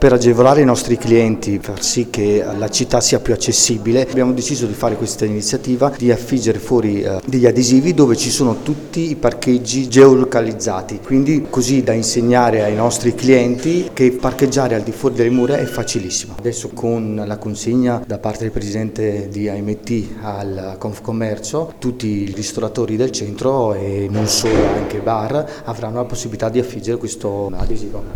ristoratore veronese